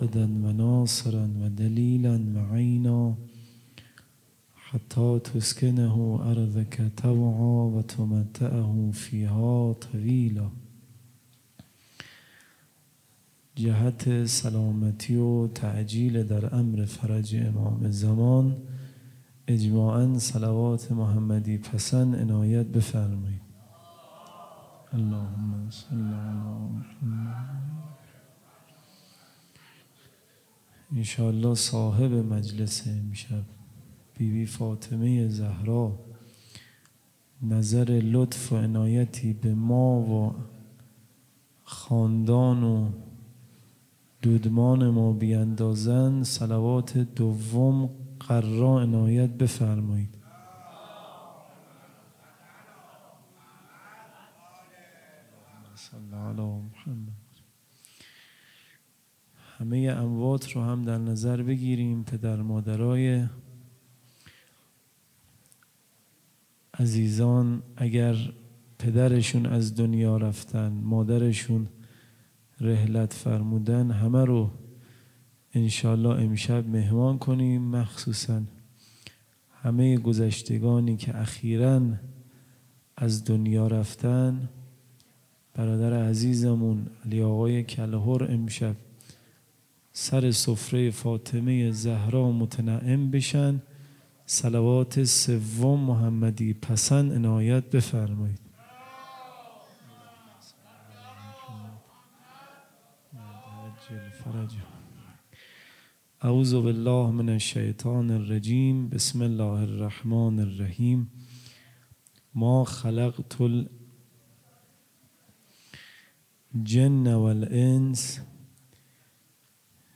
خیمه گاه - هیئت محبان الحسین علیه السلام مسگرآباد - سخنرانی